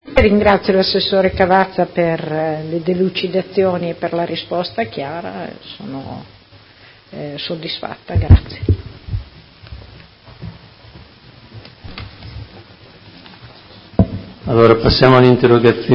Seduta del 19/09/2019 Replica a risposta Assessore. Interrogazione Prot. Gen. n. 199984 della Consigliera Santoro (Lega Modena) avente per oggetto: Vendita Farmacie comunali